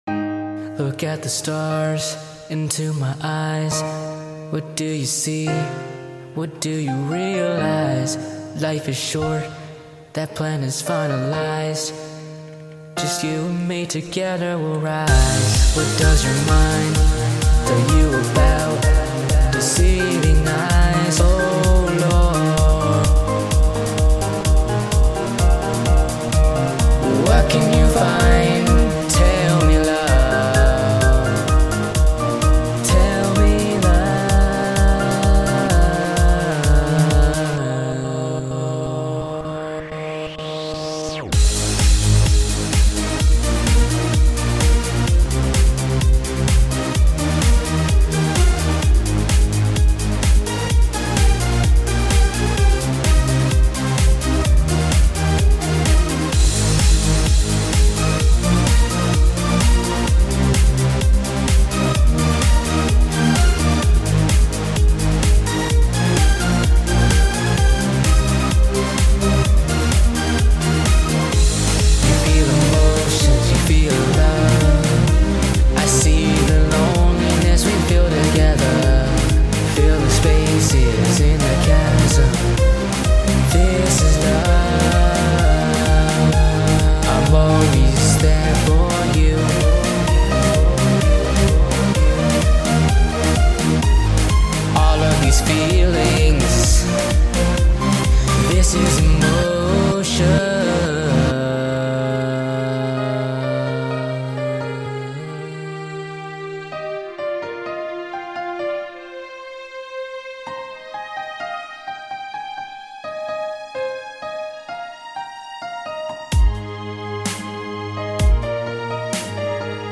Kind of experimental for me too style wise.